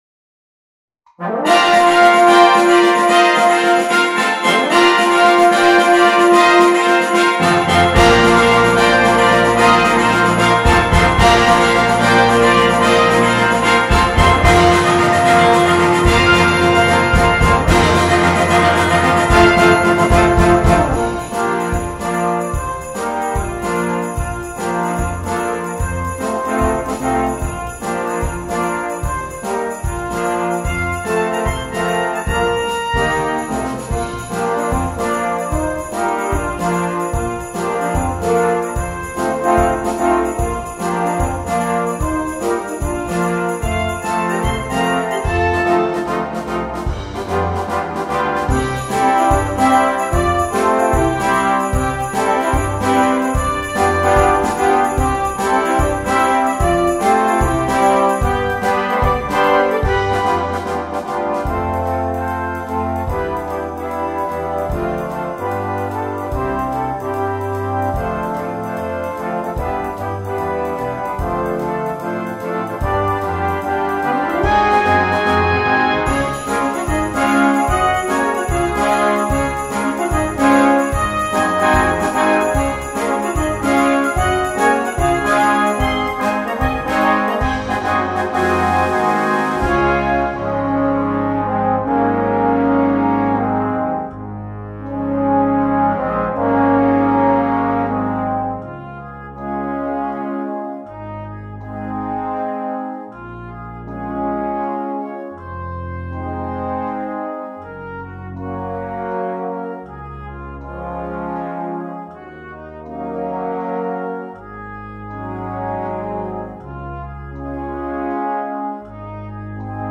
2. ブラスバンド
フルバンド
ソロ楽器なし
元の組成, 軽音楽